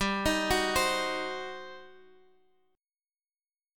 GM7sus4#5 Chord